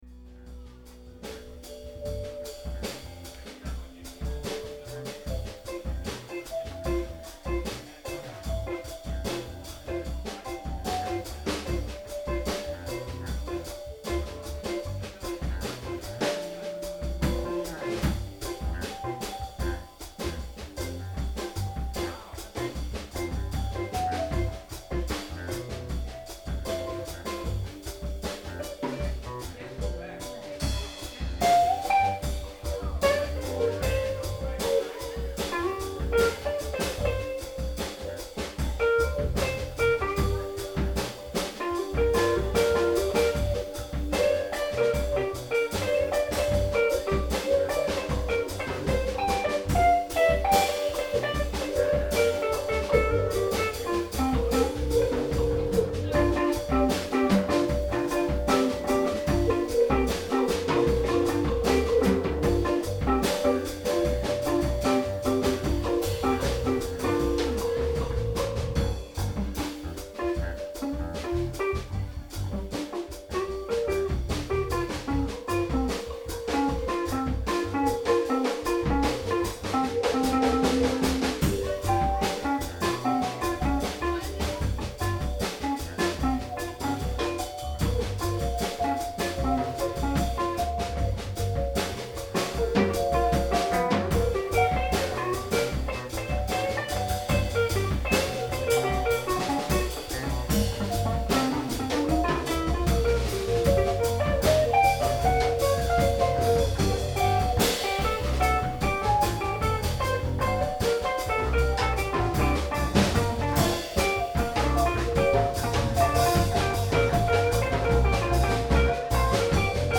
is stereo